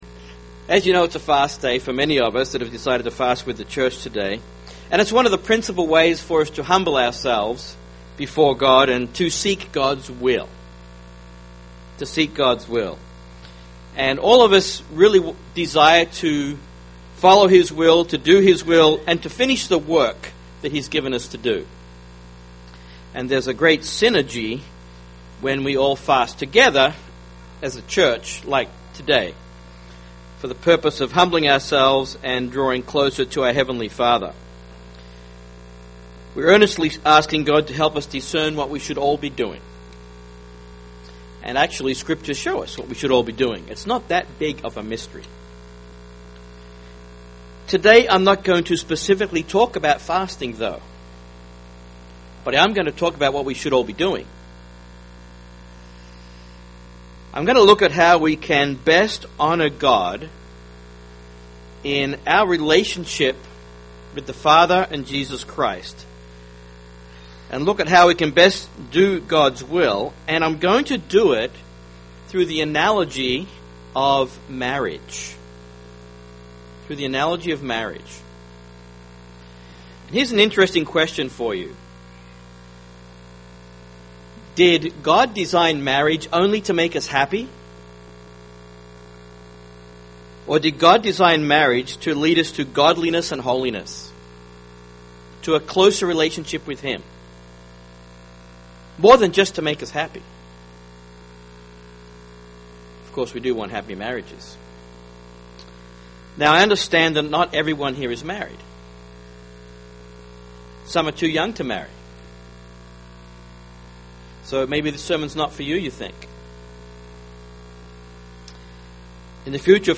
UCG Sermon marriage covenant Transcript This transcript was generated by AI and may contain errors.